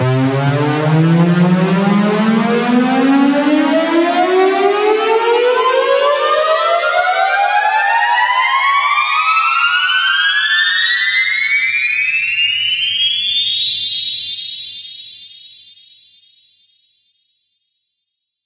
激进的上升者/上升者 128 BPM / Key G
描述：这是一种用血清制成的升级器，用第三方效果处理。声音最适合在EDM轨道的构建部分中，以在部分更改之前产生张力。
标签： 声音效果 隆起 立管 声音设计 建造 音乐 舞蹈 EDM 效果 FX 电舞音乐
声道立体声